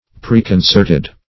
Preconcerted \Pre`con*cert"ed\, a.